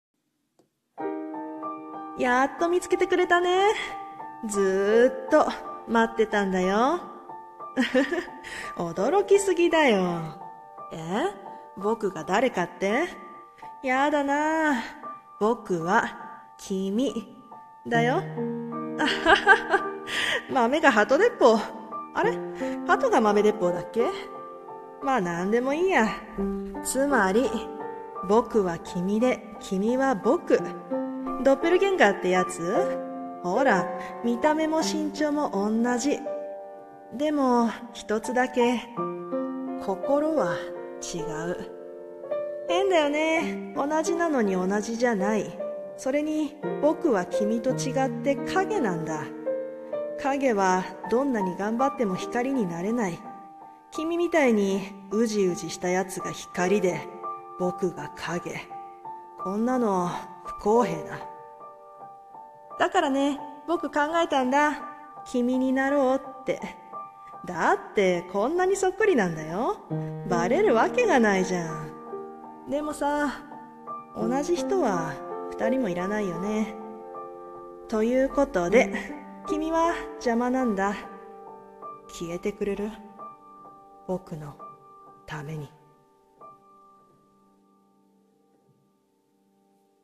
【声劇】ドッペルゲンガー